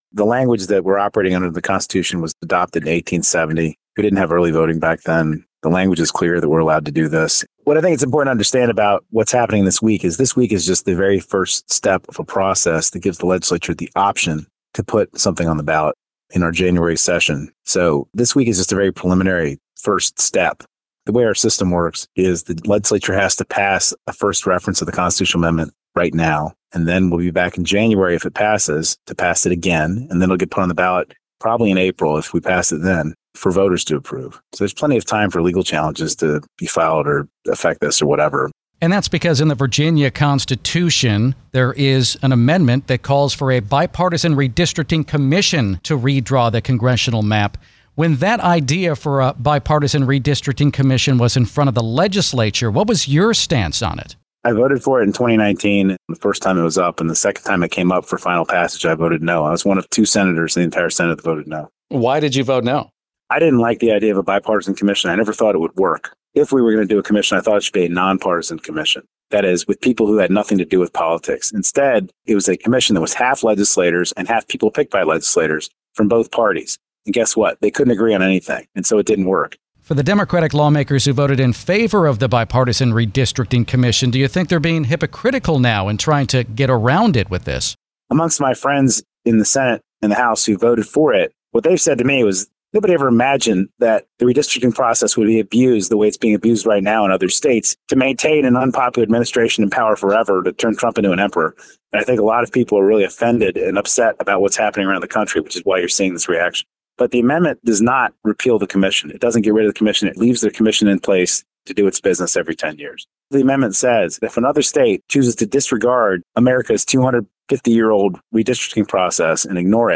WTOP is hearing from both parties in the battle over redistricting in Virginia.